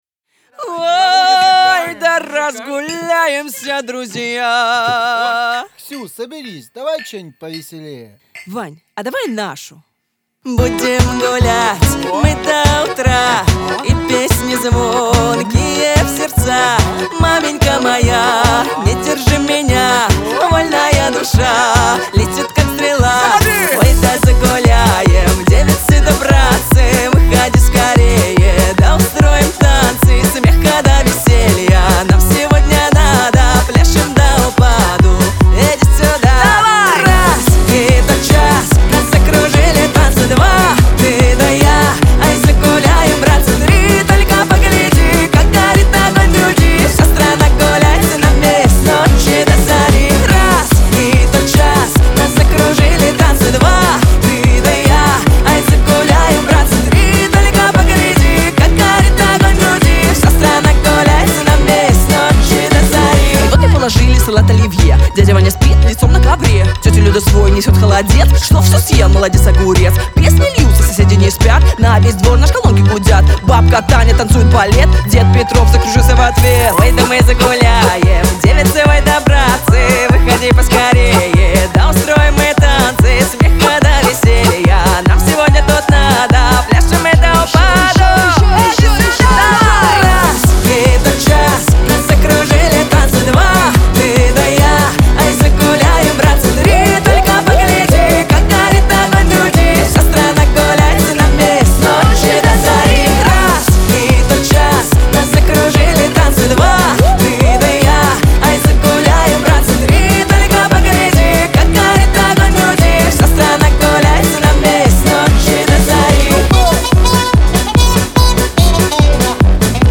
Лирика , Веселая музыка